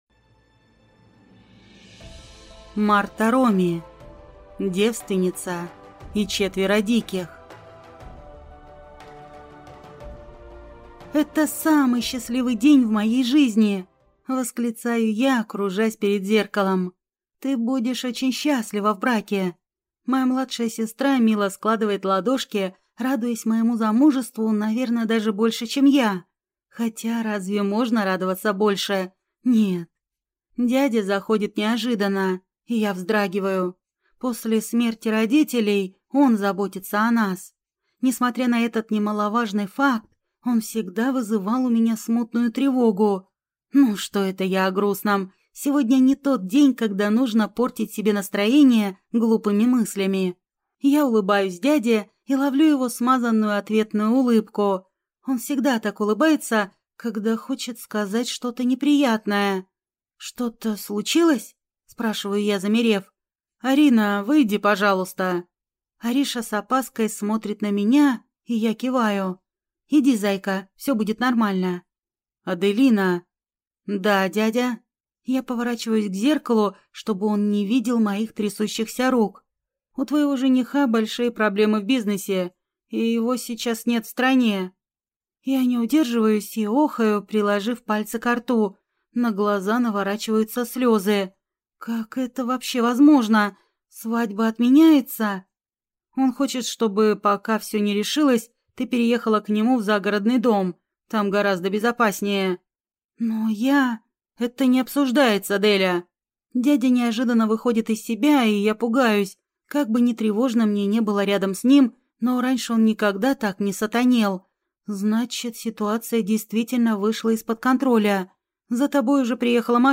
Аудиокнига Девственница и четверо диких | Библиотека аудиокниг